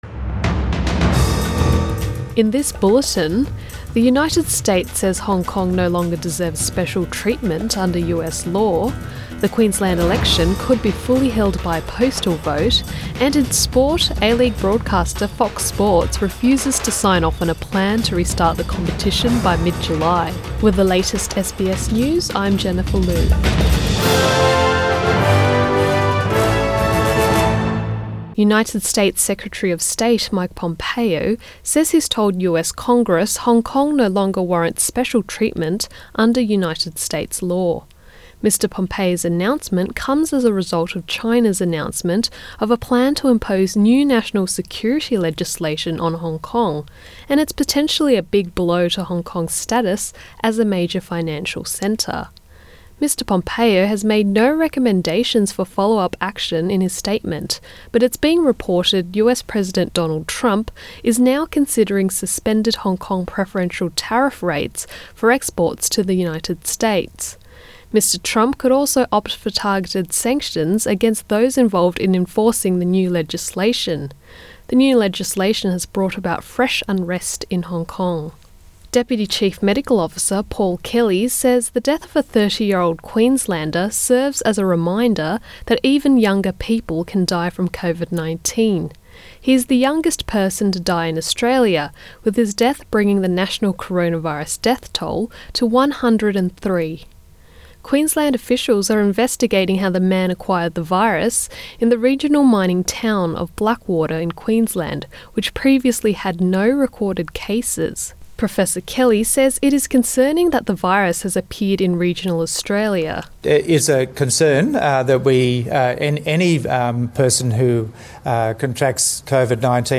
AM bulletin 28 May 2020